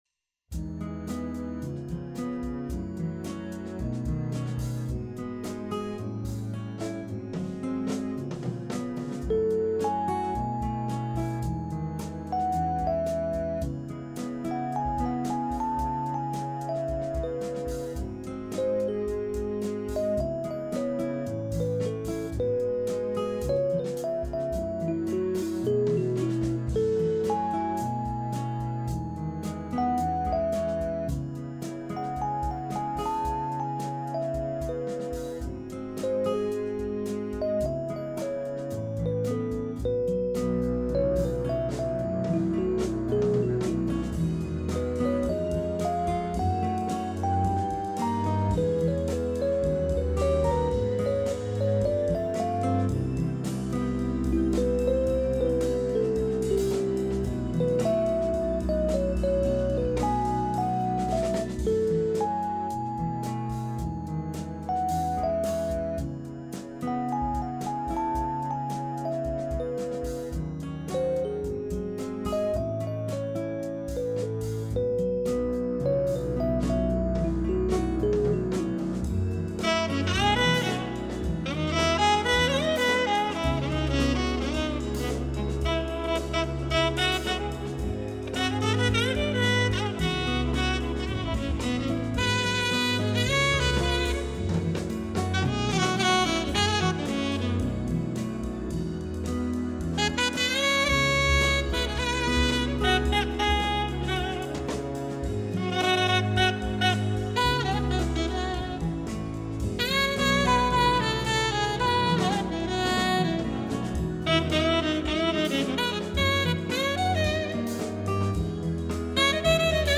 今回は、「ニューエイジスマートジャズ」という「スタイル」を選択。
正直、ニューエイジ・ミュージックって云うより、中途半端なフュージョンみたいですが…。（汗）